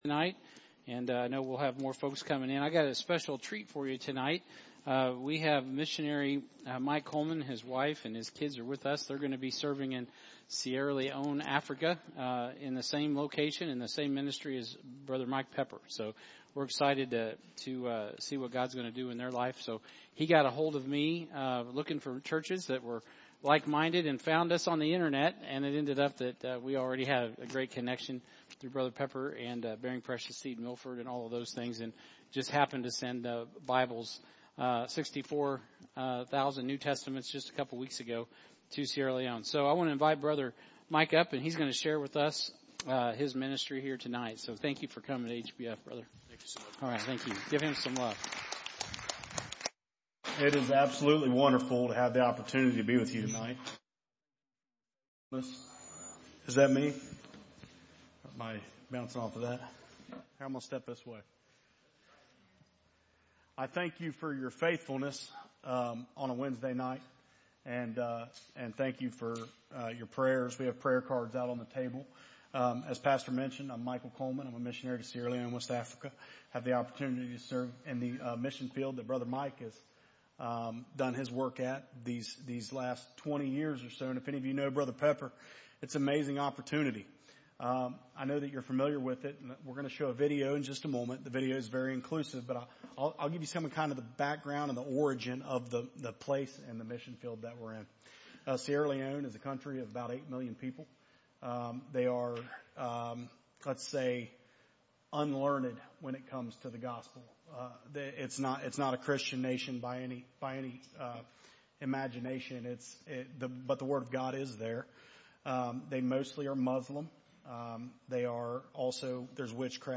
Revelation 22:1-5 Sermon Notes